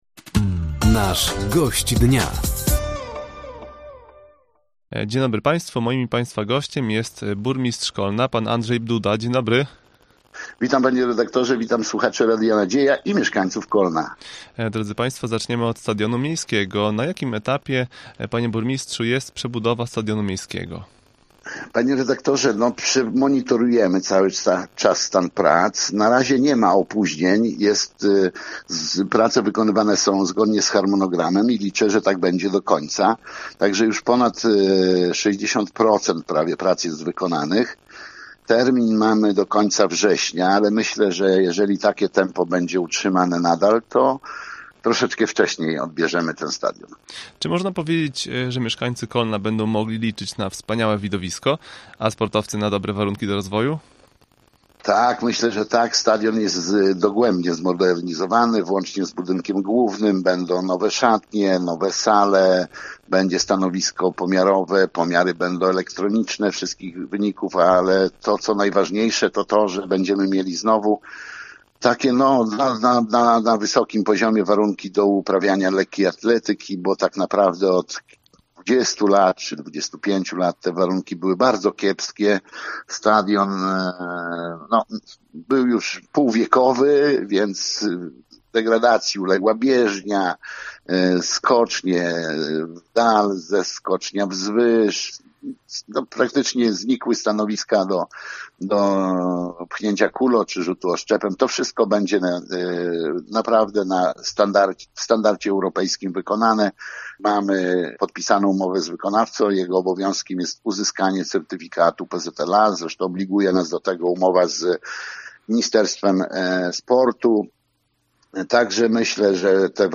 Burmistrz Kolna, Andrzej Duda był Gościem Dnia Radia Nadzieja. Na początku rozmowy burmistrz przypomniał o przebudowie stadionu miejskiego. Rozmowa dotyczyła również inwestycji drogowych oraz zaproszenia na Dzień Dziecka.